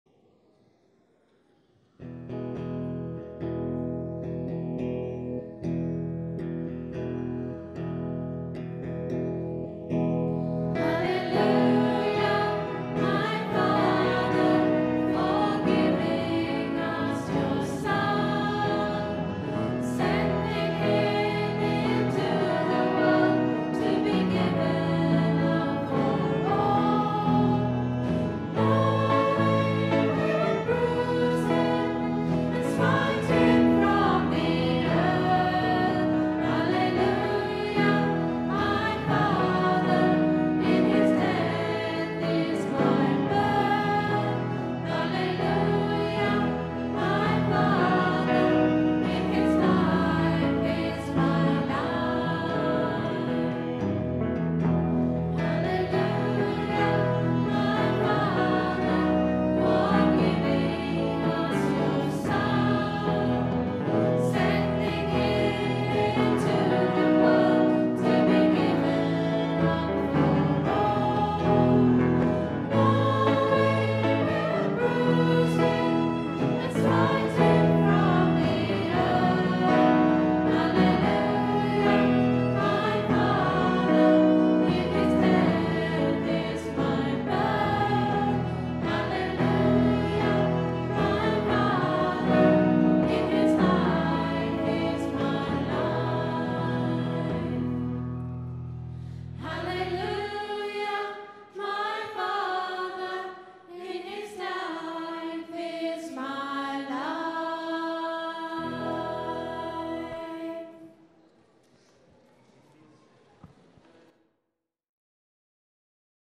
Recorded on a Zoom H4 digital stereo recorder at 10am Mass Sunday 12th September 2010.